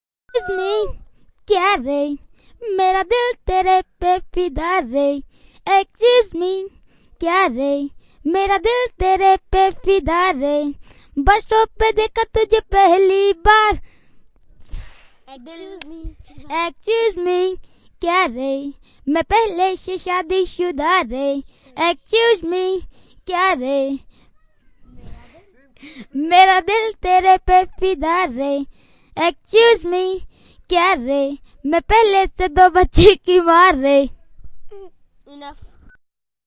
描述：在印度达姆萨拉录制的2002年。孩子们演唱电影中的歌曲和儿童歌曲。
Tag: 宝莱坞 印度 孩子